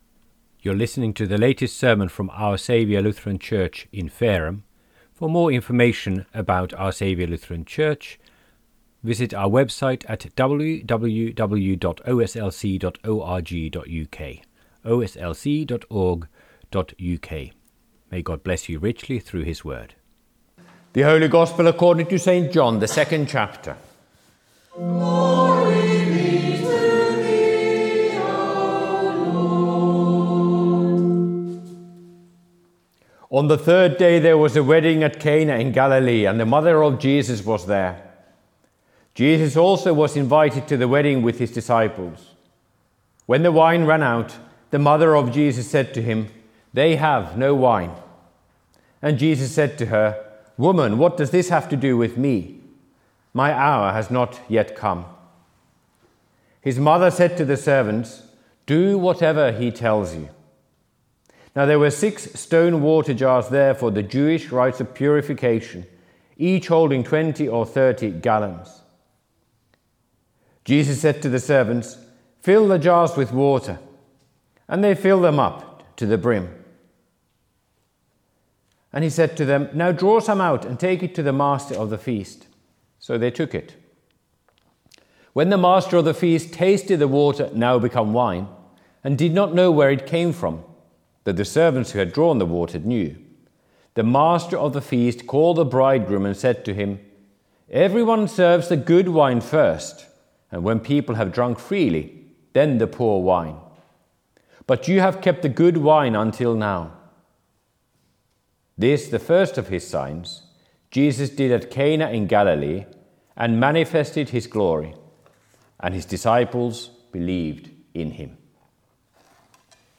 Dec 18, 2025 | Sermons, Advent, Advent 3, Midweek Vespers